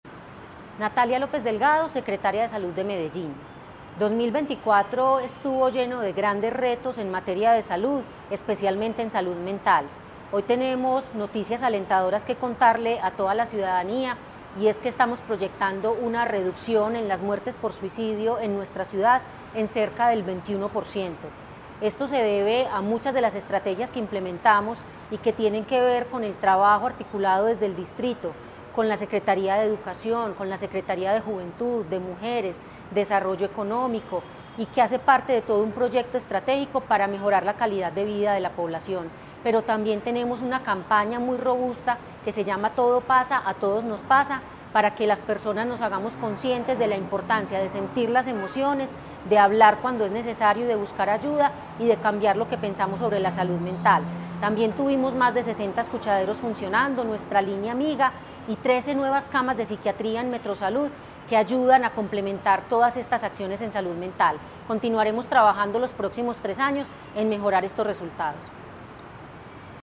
Palabras de Natalia López Delgado, secretaria de Salud